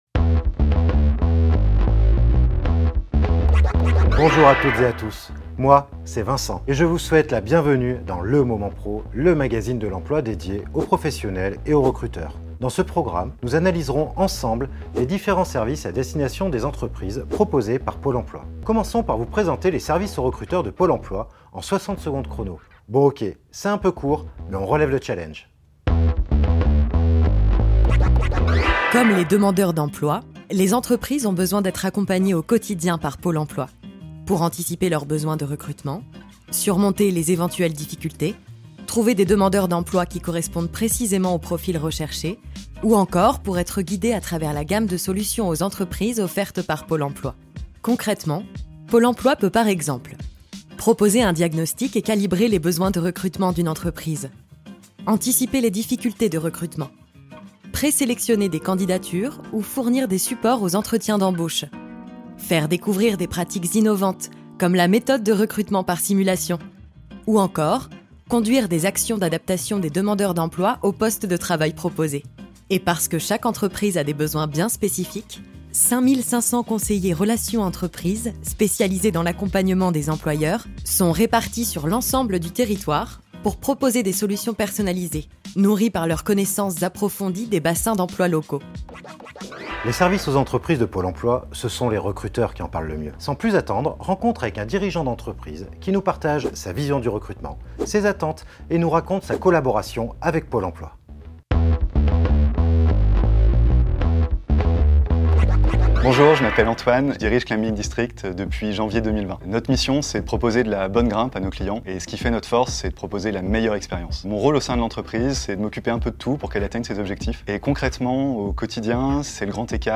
Découvrez la gamme de services aux entreprises de Pôle emploi dans le talk-show « Le Moment PRO » qui s'articule autour de plusieurs rubriques rythmées !